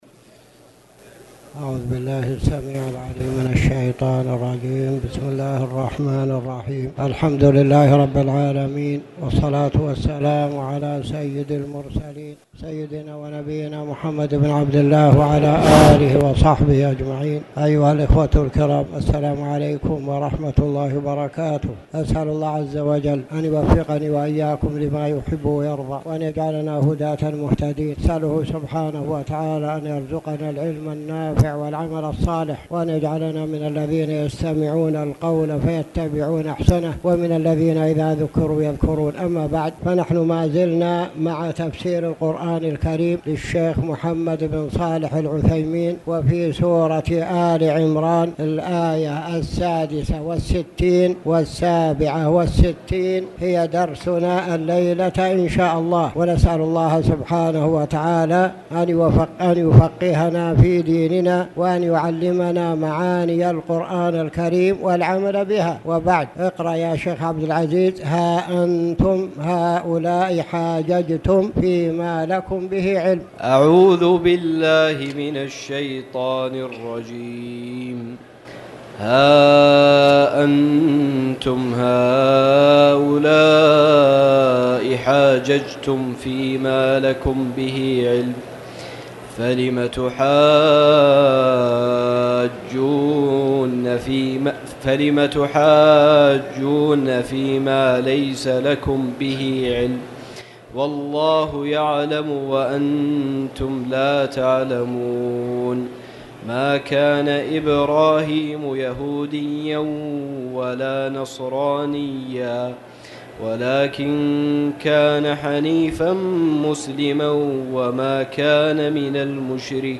تاريخ النشر ٨ رمضان ١٤٣٨ هـ المكان: المسجد الحرام الشيخ